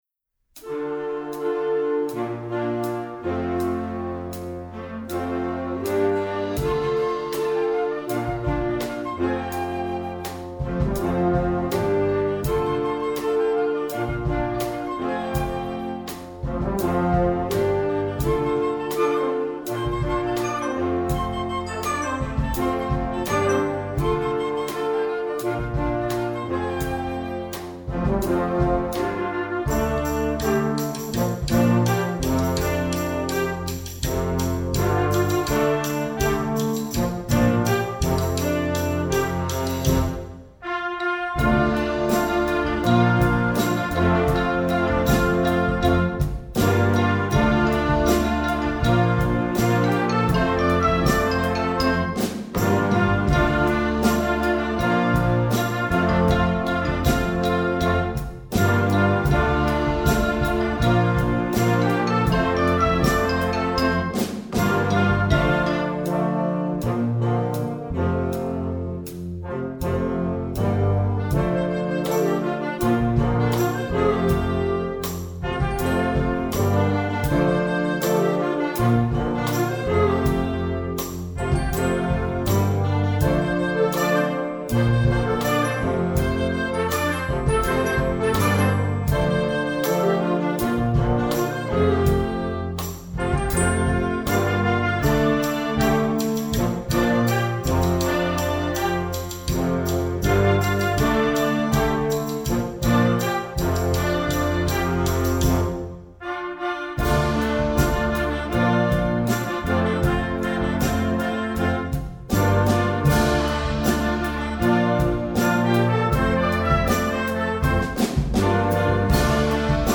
film/tv, pop, rock, movies